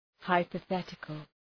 Προφορά
{,haıpə’ɵetıkəl} (Επίθετο) ● υποθετικός